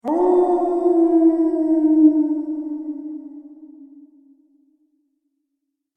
دانلود صدای گرگ 3 از ساعد نیوز با لینک مستقیم و کیفیت بالا
جلوه های صوتی